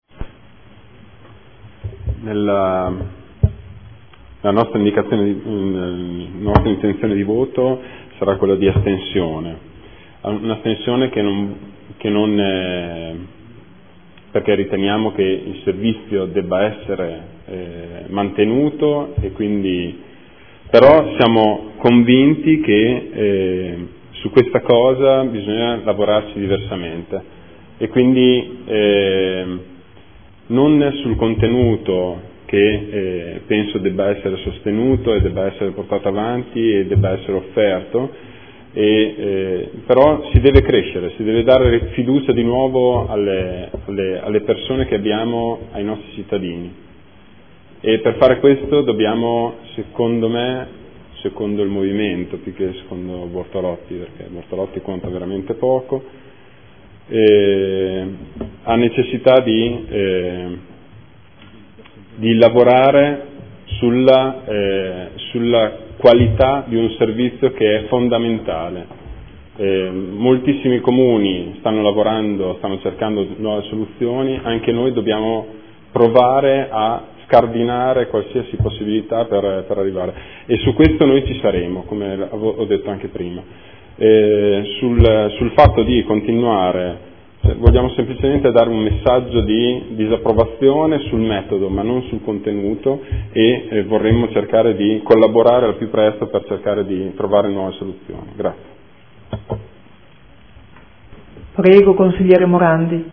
Marco Bortolotti — Sito Audio Consiglio Comunale
Seduta del 11/12/2014 Dichiarazione di voto. Linee di indirizzo per l’appalto per la gestione di attività e progetti orientati alla coesione sociale – Periodo dall’1.4.2015 al 30.9.2016